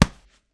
Gloves Block Normal.wav